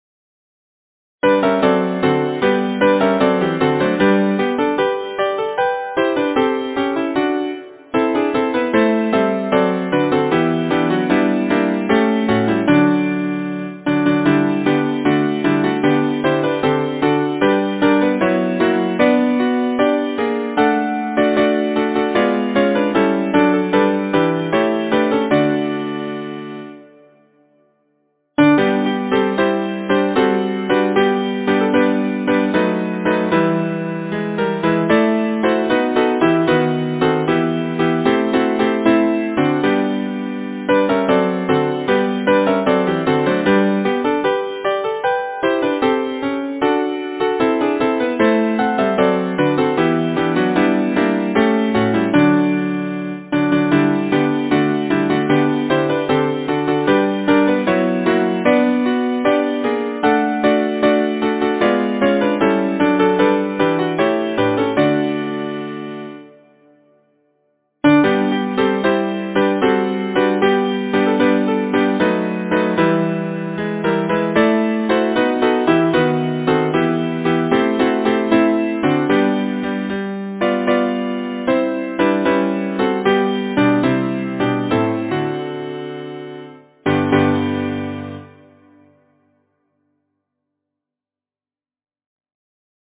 Title: Cobwebs Composer: Alfred James Caldicott Lyricist: S. K. Cowancreate page Number of voices: 4vv Voicing: SATB Genre: Secular, Partsong
Language: English Instruments: A cappella